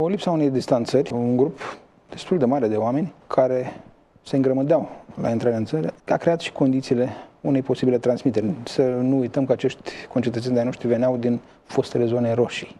Ministrul Sănătăţii, Nelu Tătaru, a atras atenţia asupra pericolului pe care îl presupune această situaţie: